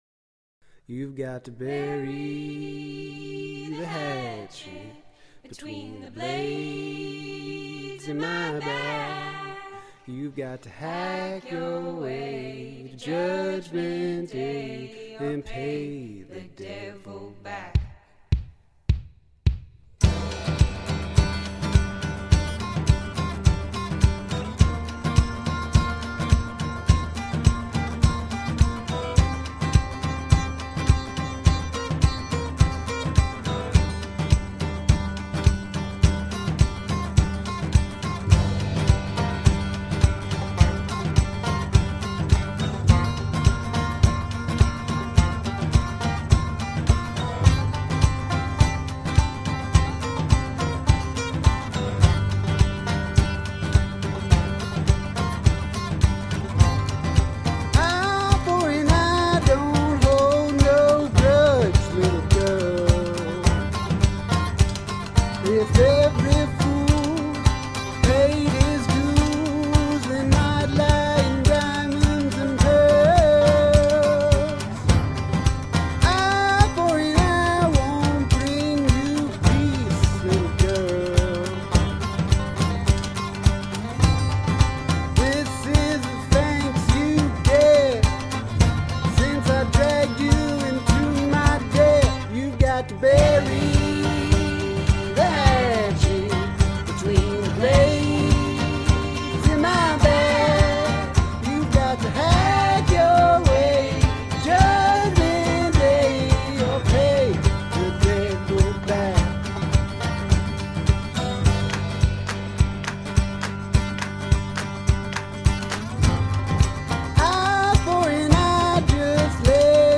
swamp inspired roots flavored music